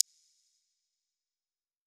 menu sounds